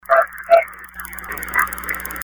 EVP's
Littlegirlandhelpme.mp3